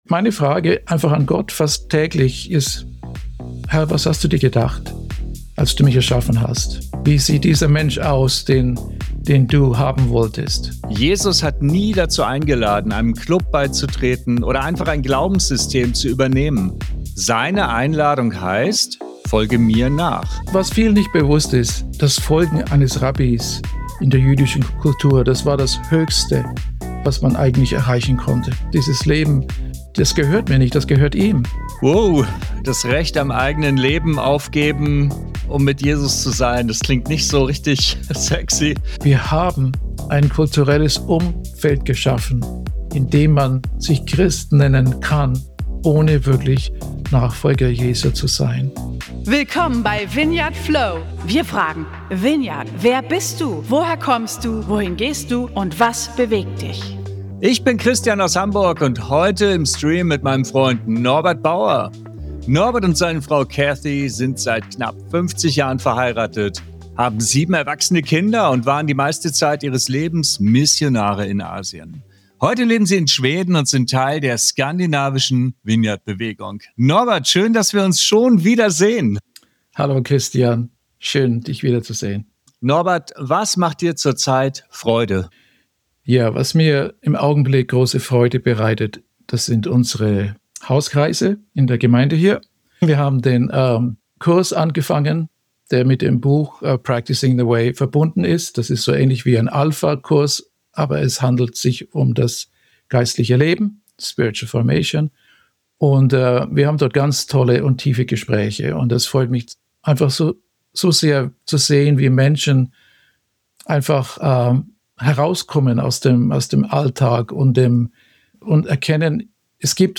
Das Gespräch taucht in die Vorstellungen von Vertrautheit, Verwandlung und Verantwortung ein, die mit dem Leben eines Nachfolgers verbunden sind.